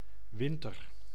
Ääntäminen
UK : IPA : /ˈwɪntə(ɹ)/ US : IPA : /ˈwɪntɚ/ IPA : [ˈwɪɾ̃ɚ]